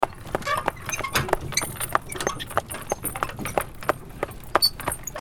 Horse Sounds - Free Download MP3 | Orange Free Sounds
Clip-clop-sound-effect.mp3